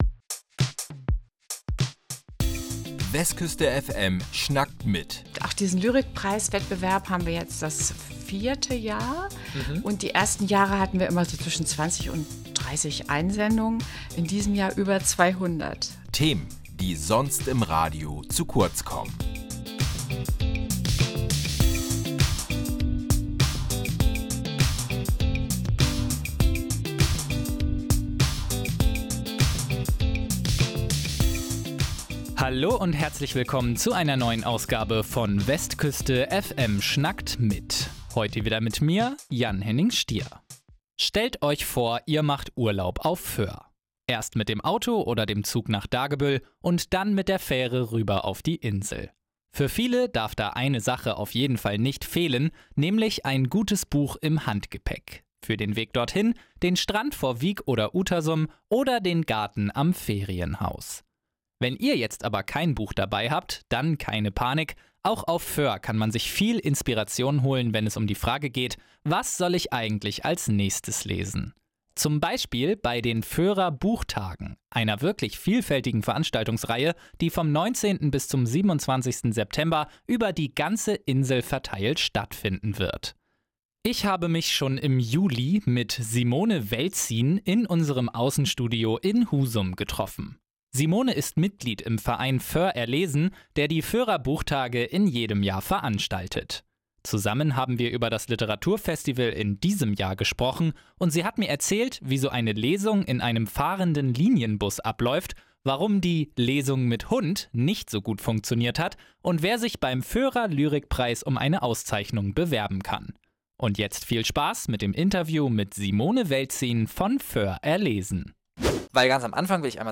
Am 31. Juli 2025 war sie bei uns im Westküste FM-Außenstudio in Husum und hat uns von der diesjährigen Ausgabe erzählt.